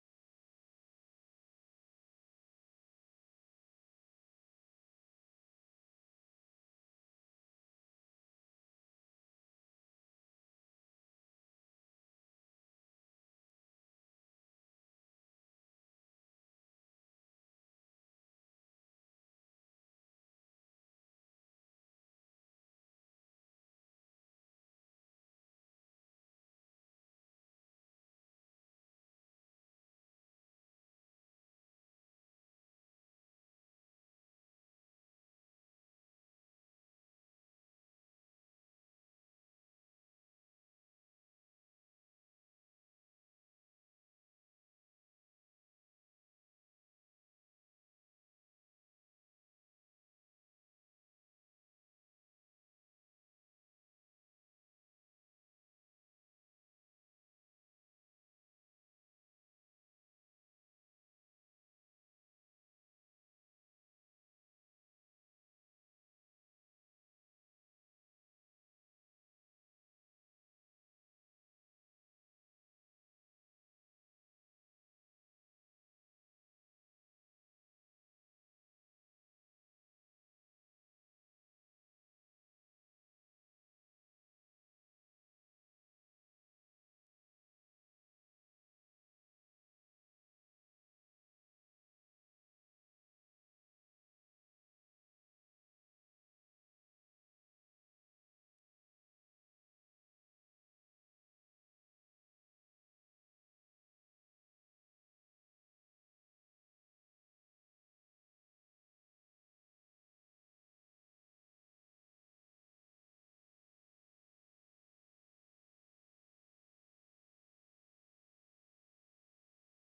LIVE Evening Worship Service - Disciples
Congregational singing—of both traditional hymns and newer ones—is typically supported by our pipe organ.